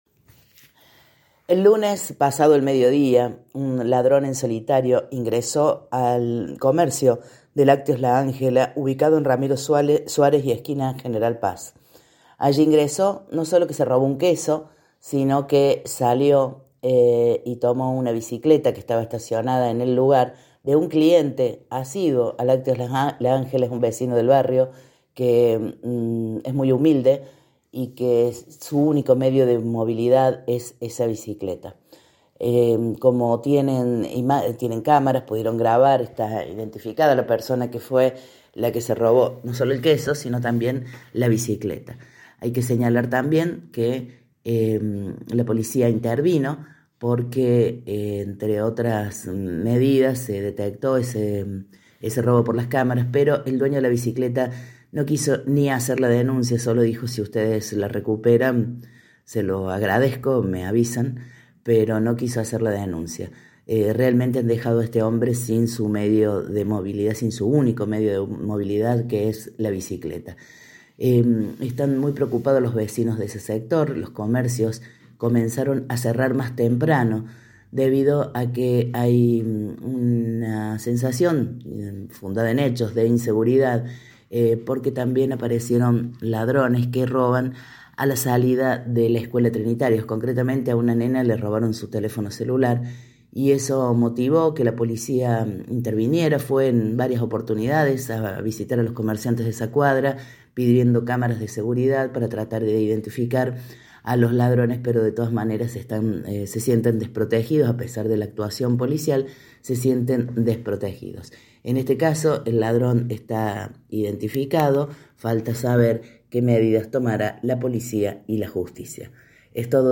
Audio: Informe